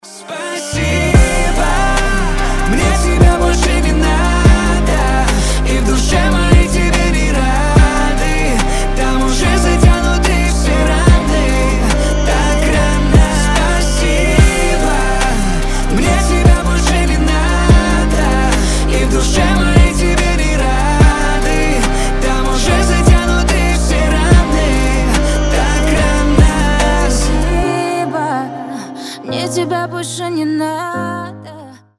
• Качество: 320, Stereo
грустные
дуэт